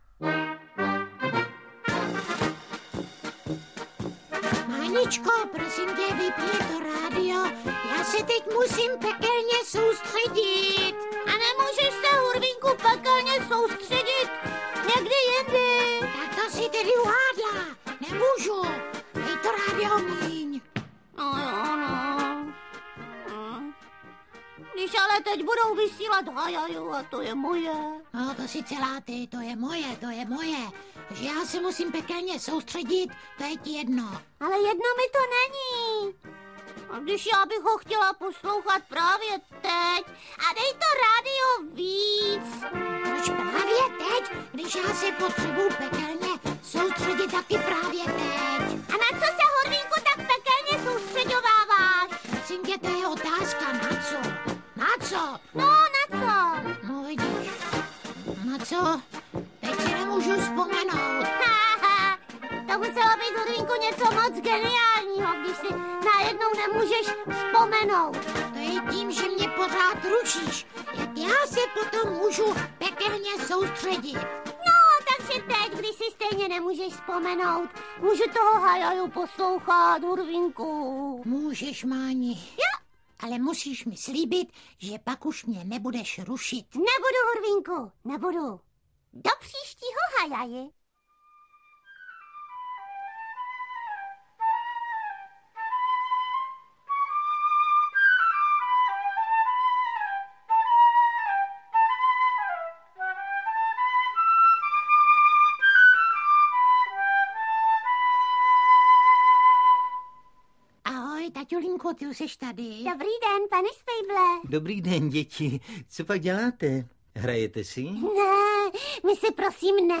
Audiokniha
Čte: Miloš Kirschner, Helena Stachová